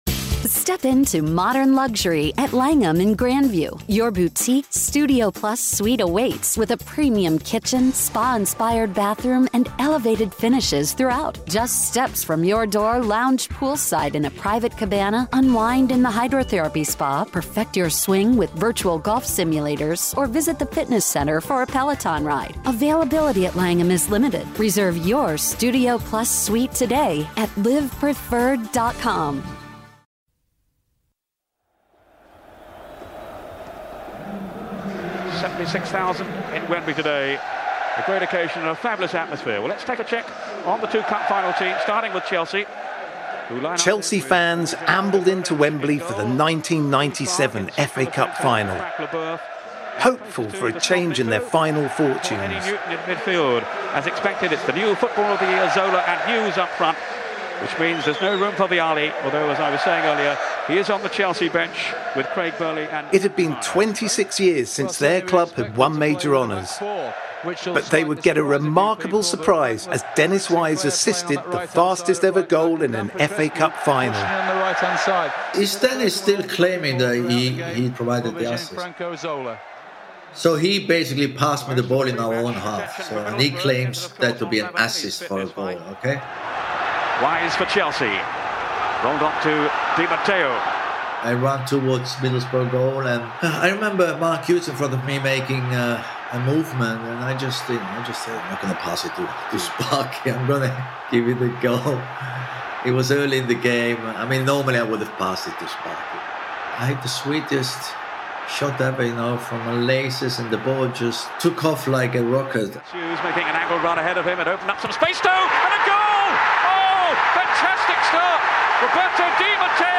Archive used in this episode is courtesy of Chelsea TV.